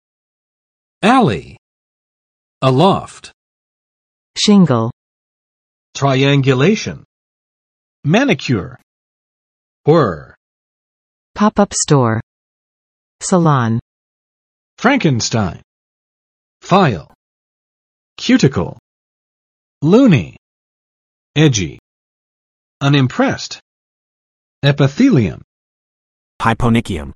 [ˋælɪ] n. 小巷，胡同；后街；（花园里的）小径
alley.mp3